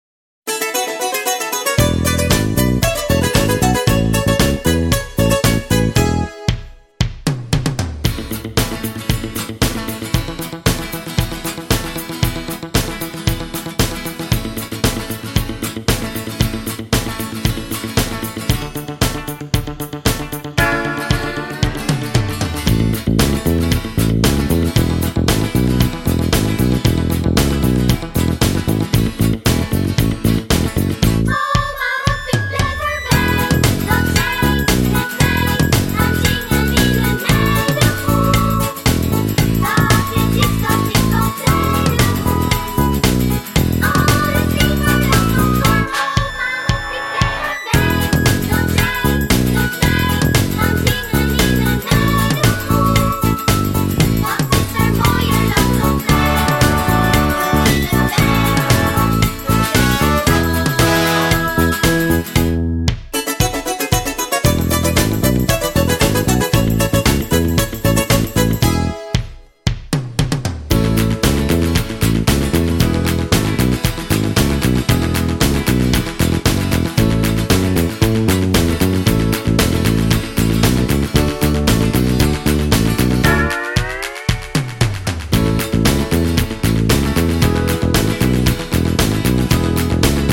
Met backing